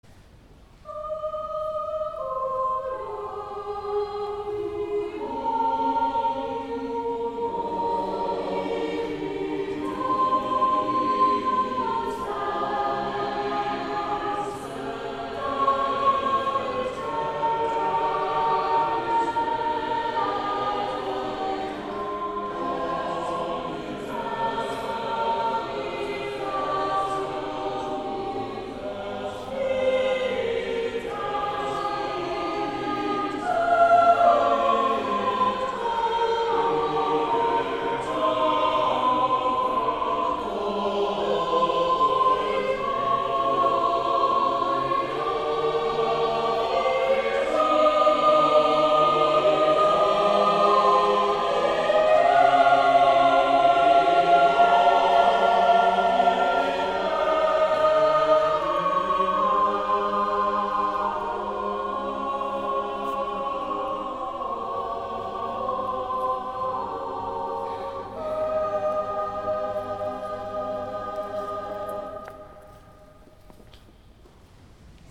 Holy Eucharist
Cathedral Choir